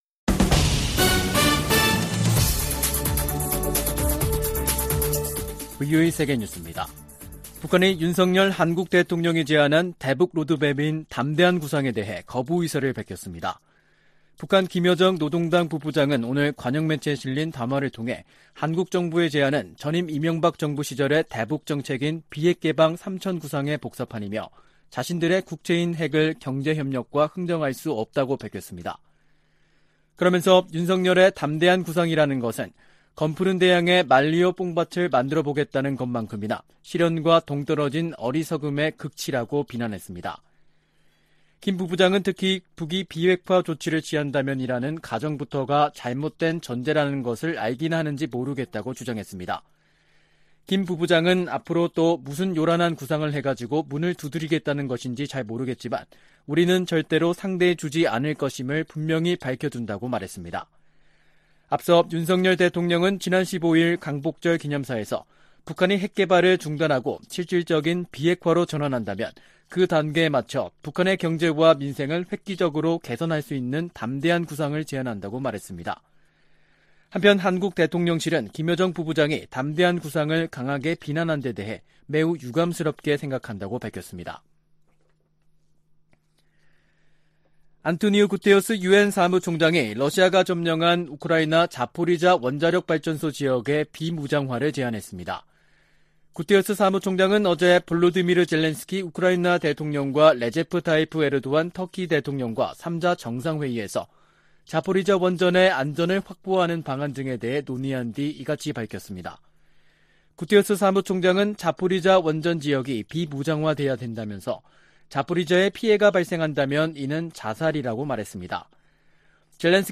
VOA 한국어 간판 뉴스 프로그램 '뉴스 투데이', 2022년 8월 19일 3부 방송입니다. 김여정 북한 노동당 부부장이 윤석열 한국 대통령의 '담대한 구상'을 정면 거부하는 담화를 냈습니다. 미 국무부는 북한이 대화 의지를 보이면 비핵화를 위한 점진적 단계가 시작되지만 이를 거부하고 있어 실질적 단계에 도입하지 못하고 있다고 밝혔습니다. 미국의 전문가들은 윤 한국 대통령의 한일 관계 개선 의지 표명을 긍정적으로 평가했습니다.